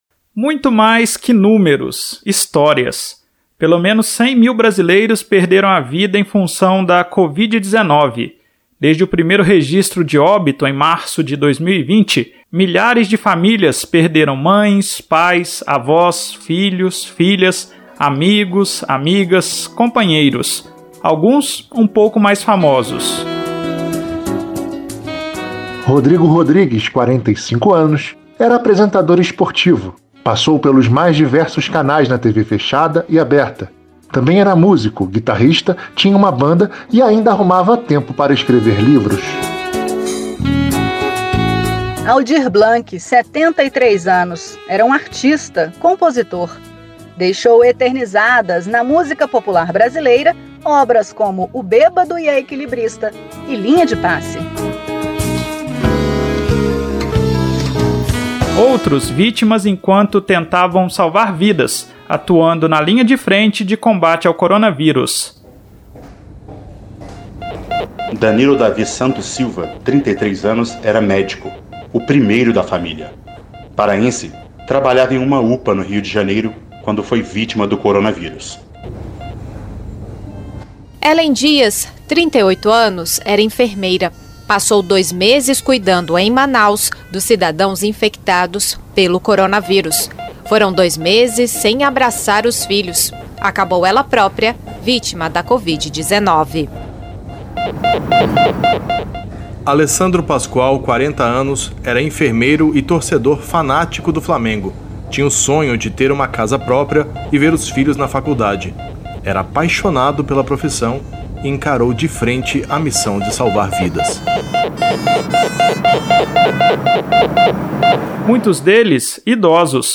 Reportagem da Rádio Senado buscou no Projeto Inumeráveis algumas histórias de vidas interrompidas de brasileiros famosos ou anônimos.